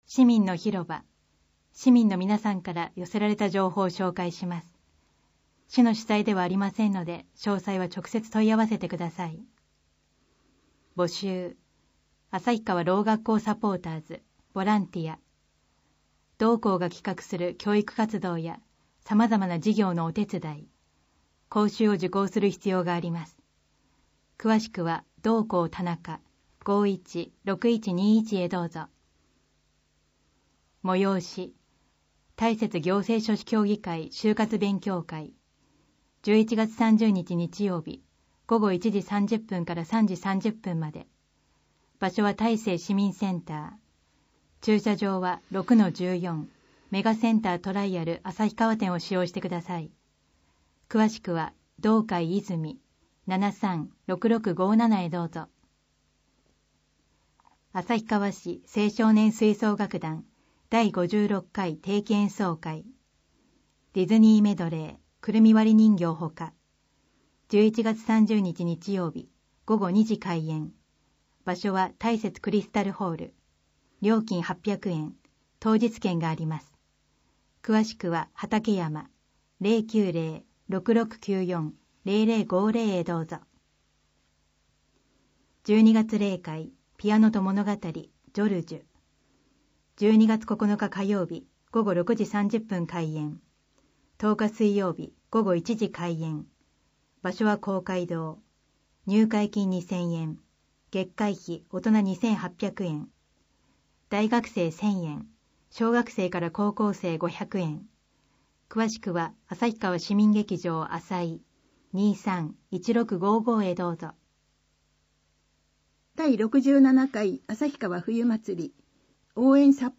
広報誌の内容を要約し、音訳した声の広報「あさひばし」を、デイジー図書版で毎月発行しています。